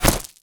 bullet_impact_gravel_05.wav